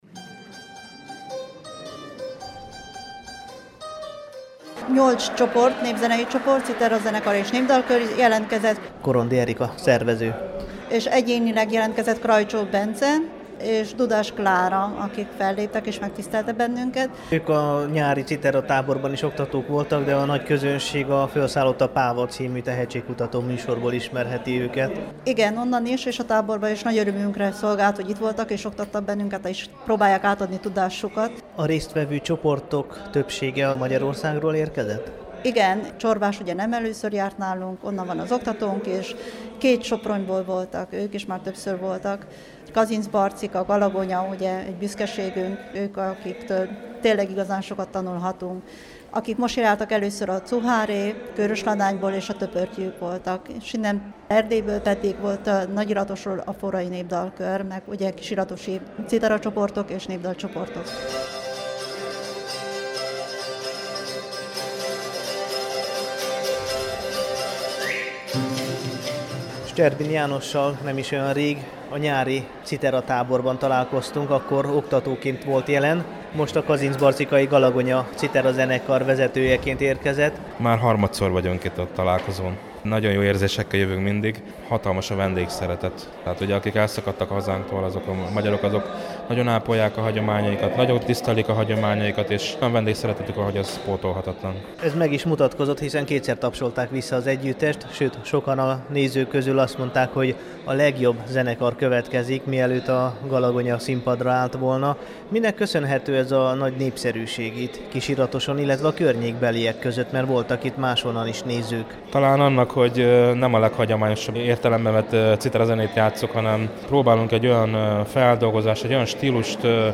Egyre színvonalasabb a kisiratosi népzenei fesztivál [AUDIÓ]
4-ik_Nepzenei_Fesztival_Kisiratoson.mp3